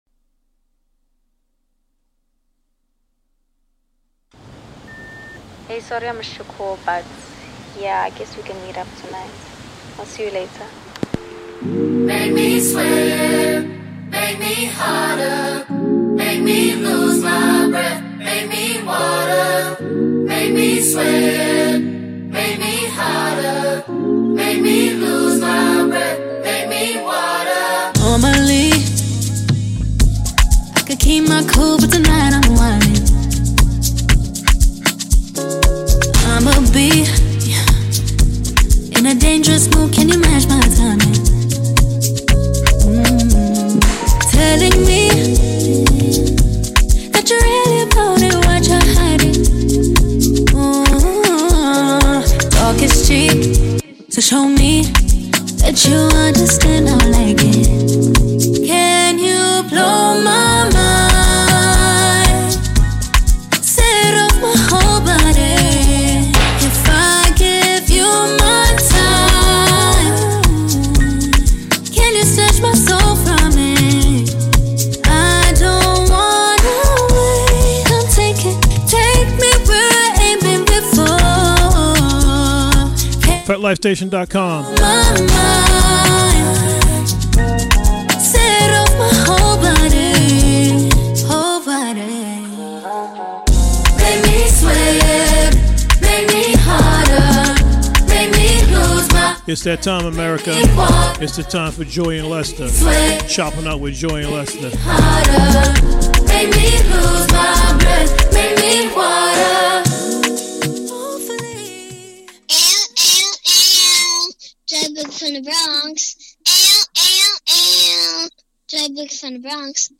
Conversation for the soul.